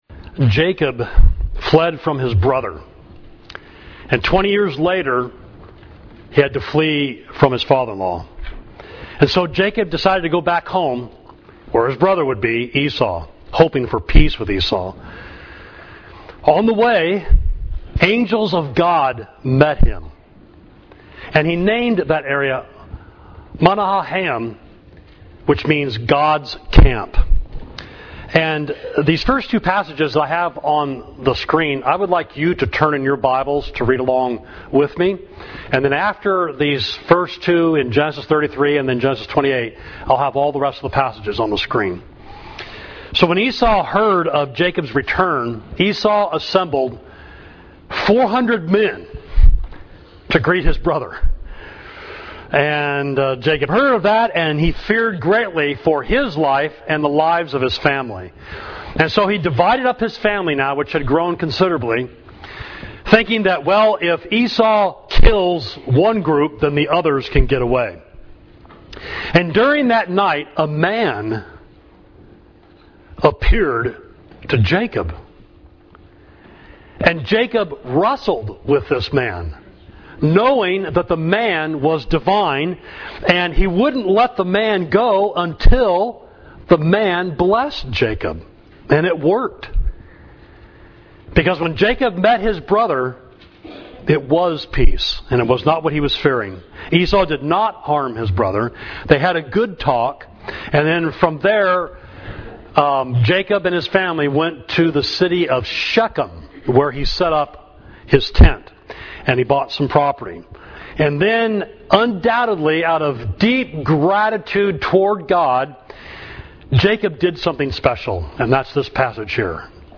Sermon: El Elohe Yisrael – God, the God of Israel, Genesis 33.20